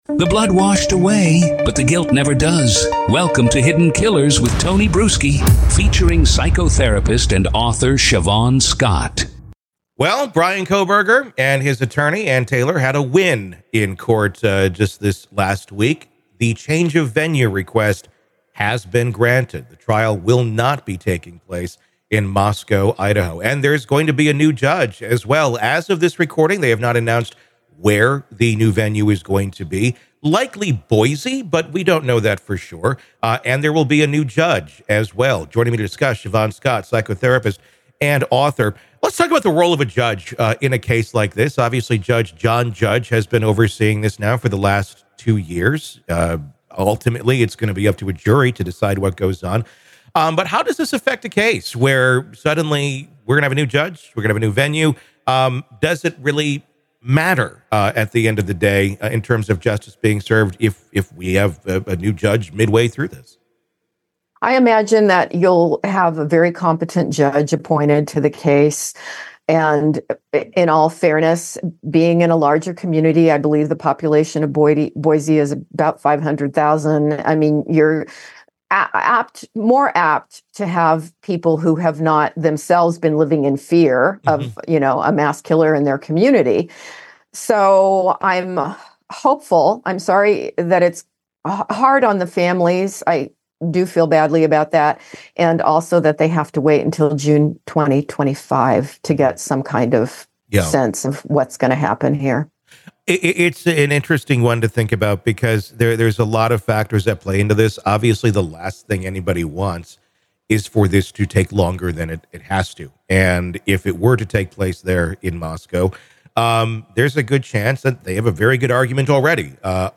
The conversation touches on the recent court decision to change the trial venue and appoint a new judge.